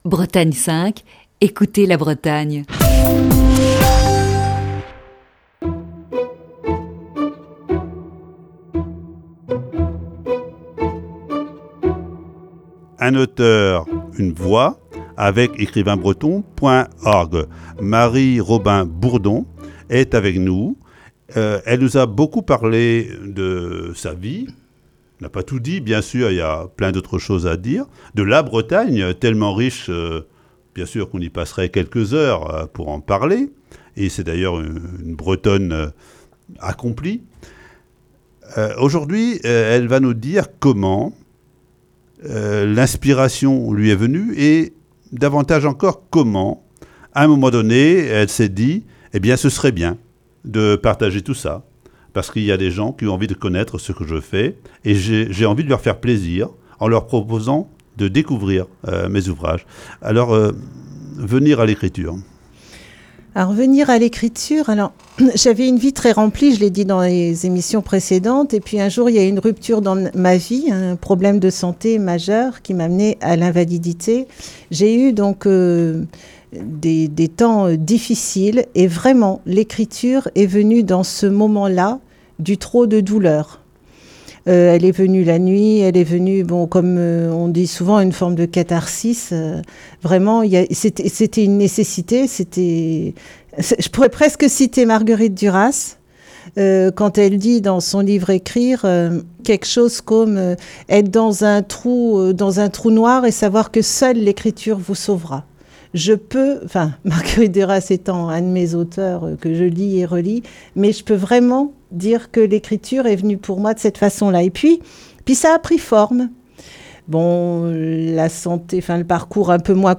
Voici ce mercredi, la troisième partie de cet entretien.